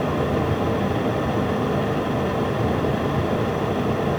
background_fan_air_vent_loop_02.wav